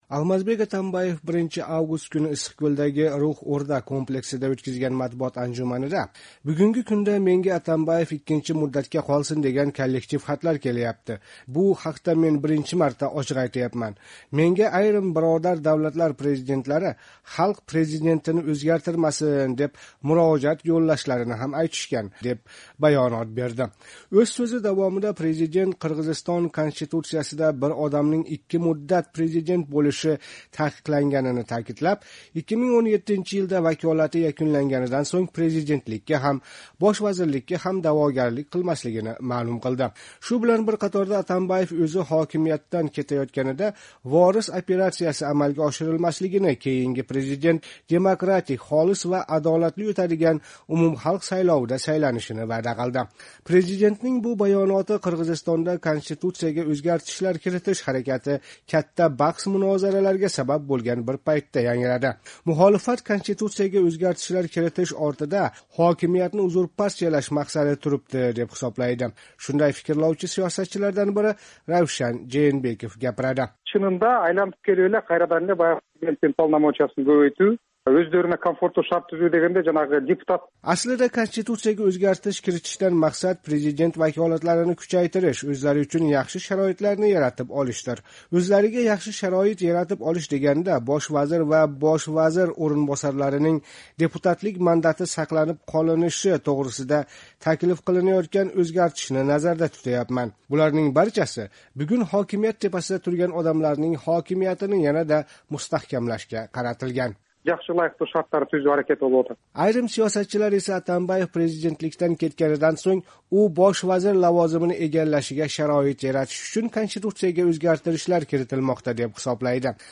Қирғизистон президенти Алмазбек Атамбаев 1 август куни Иссиқкўлда ўтказилган матбуот анжуманида мана шу гапни айтди. Ўз сўзида давом этар экан, Атамбаев президентлик муддати якунланадиган 2017 йилдан сўнг ҳокимиятда қолмаслигини яна бир бор таъкидлади.